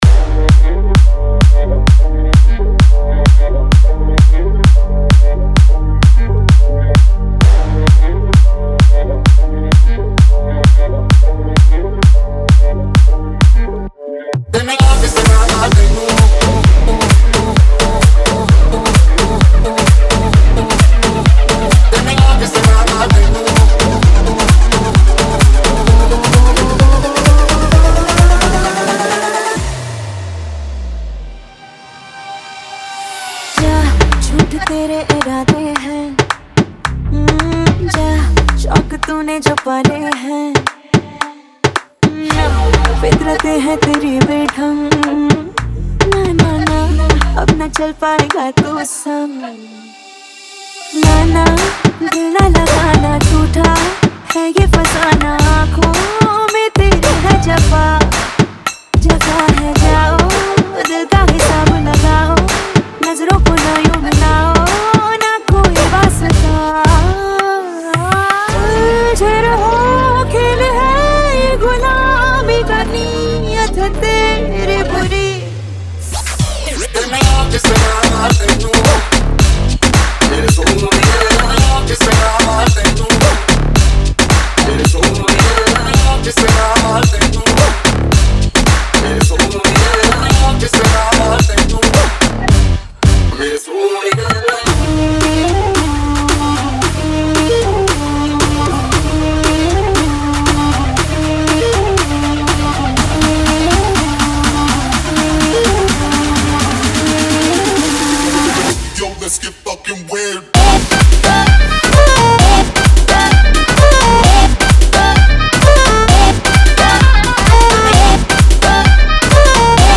2025 Bollywood Single Remixes Song Name